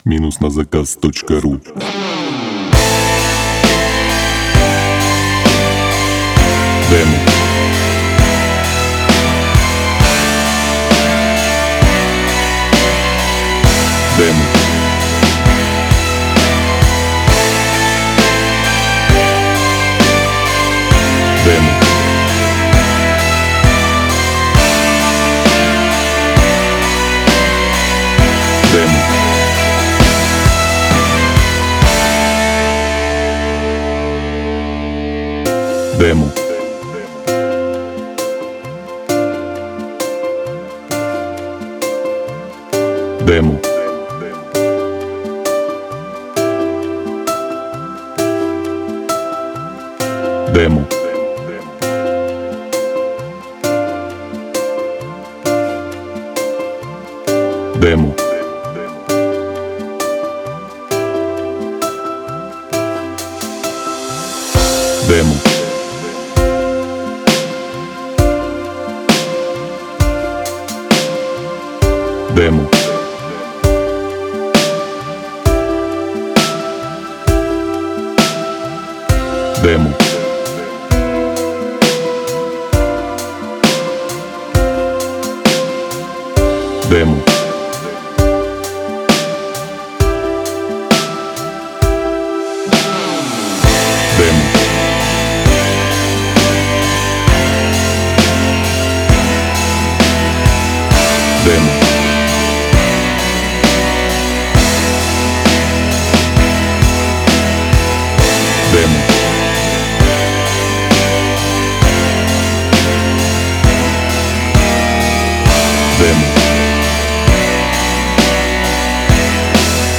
Главная » Файлы » Демо минусовок